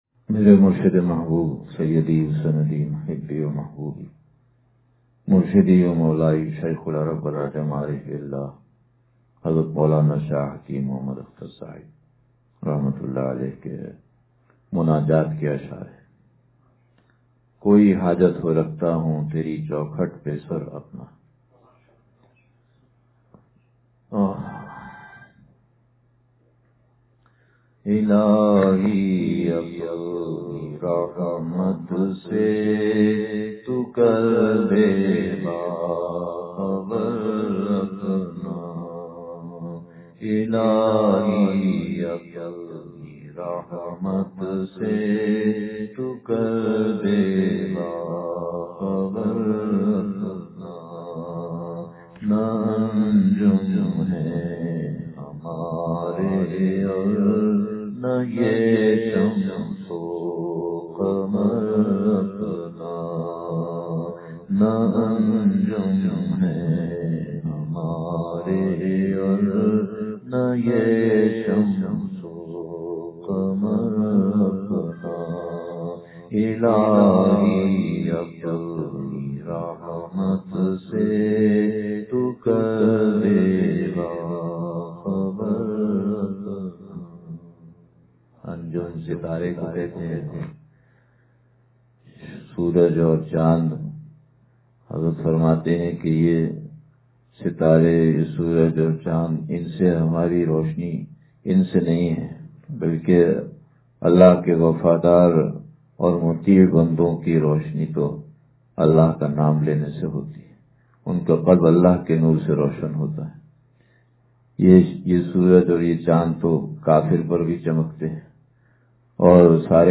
کوئی حاجت ہو رکھتا ہوں تری چوکھٹ پہ سر اپنا – اتوار بیان